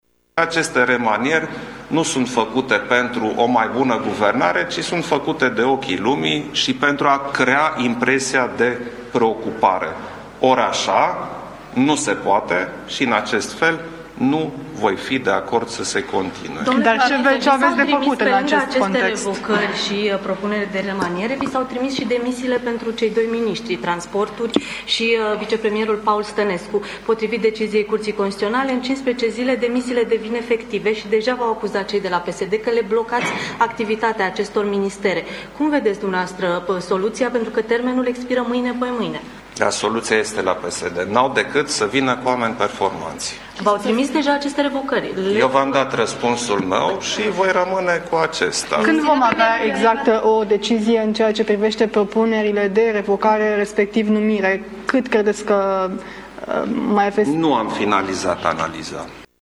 În cadrul unei declaraţii de presă susţinute la Palatul Cotroceni, după lansarea în dezbatere publică a rezultatelor proiectului ‘România Educată’, președintele Klaus Iohannis a anunțat că nu a luat o decizie privind remanierea, dar are o concluzie: PSD sub conducerea lui Dragnea nu e capabil să genereze un guvern competent.